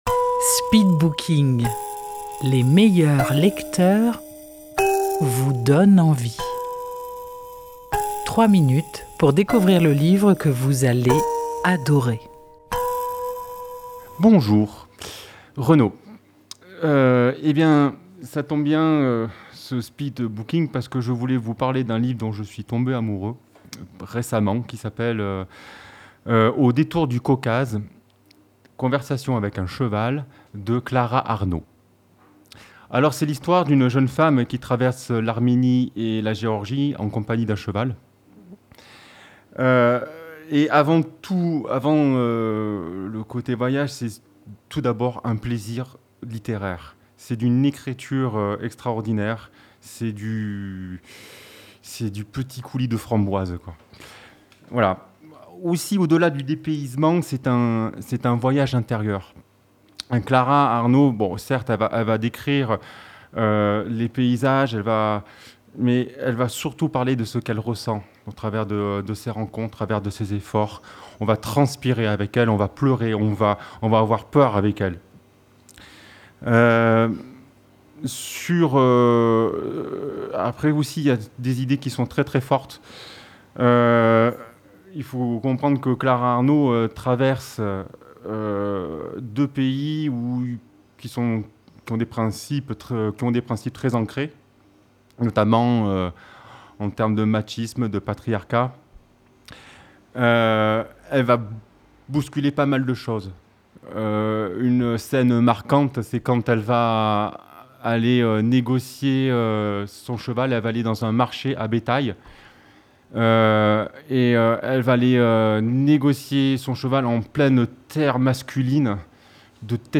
Enregistré en public au Bar & Vous à Dieulefit.